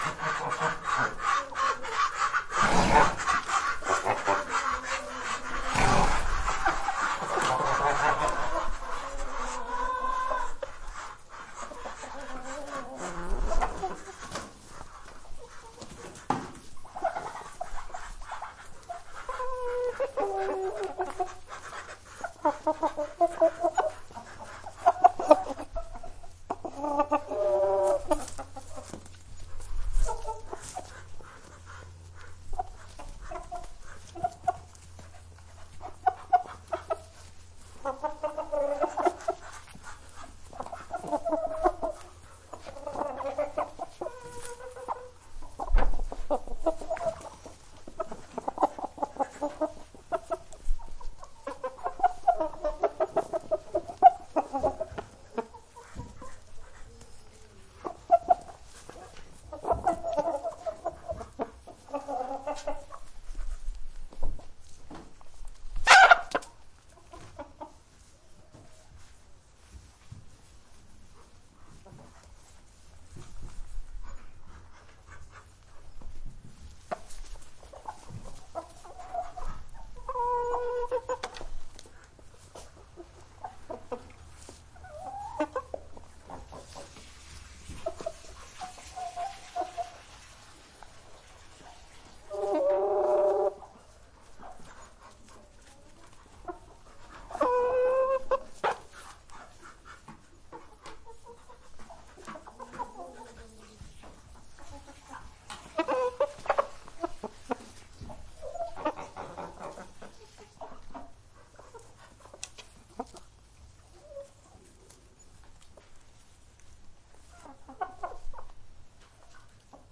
呱呱叫的鸭子
描述：在我邻居吠叫的喧嚣声中，经过交通和唱歌的鸟儿，你可以听到在我厨房窗外的宁静河里嘎嘎叫着一大群鸭子（我不知道这一天的种类）。我用迷你联想笔记本电脑的内置麦克风录制了这个。
标签： 还会发出叽叽嘎嘎 嘎嘎鸭 嘎嘎叫 鸭子 嘎嘎
声道立体声